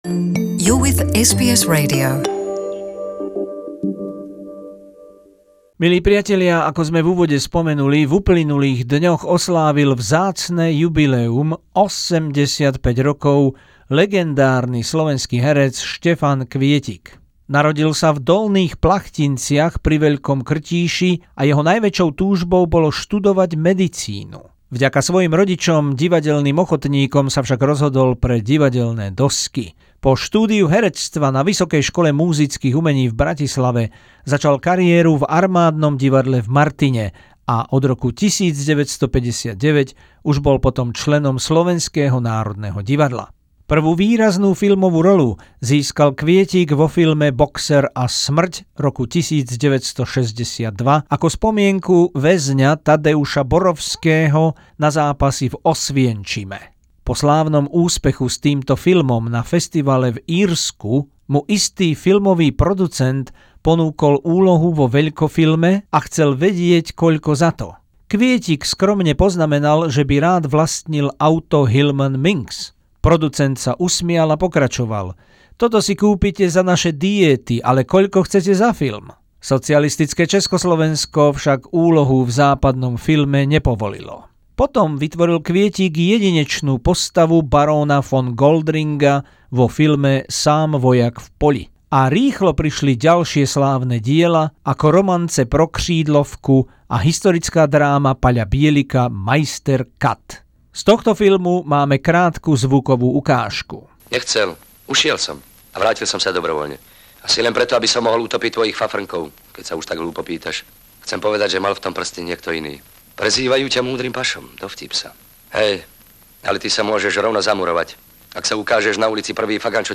Vzácna zvuková spomienka s použitím archívu pri príležitosti 85. narodenín jedného z mála stále žijúcich legendárnych slovenských hercov slávnej éry Štefana Kvietika, ktorý miluje rodnú zem, svoj slovenský národ a kultúru.